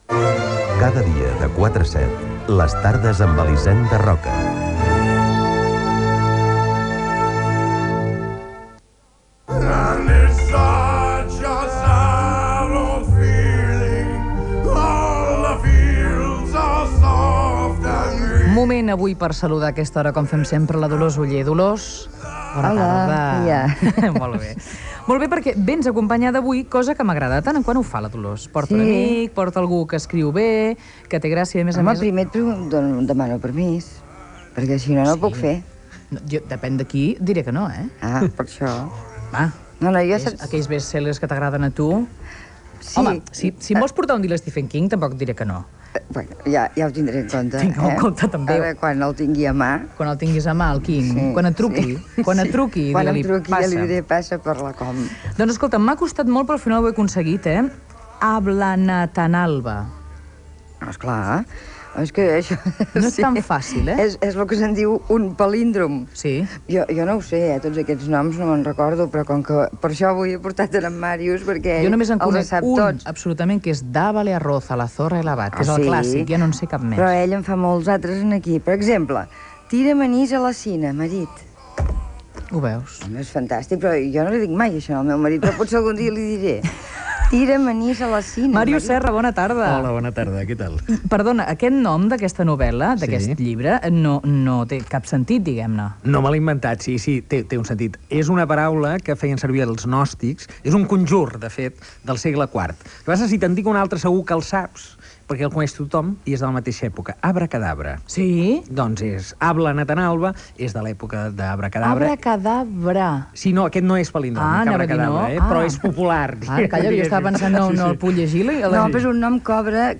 Indicatiu del programa
entrevista a Màrius Serra